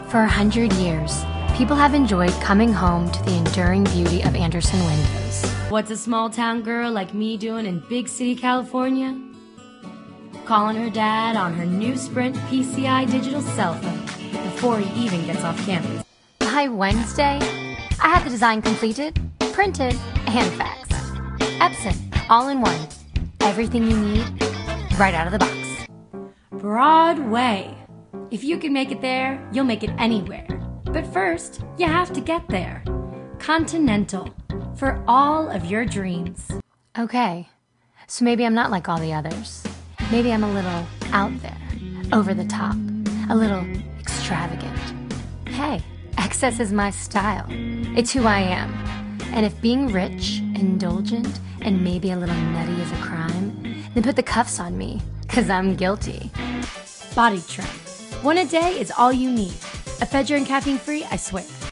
new york : voiceover : commercial : women
Commercial Demo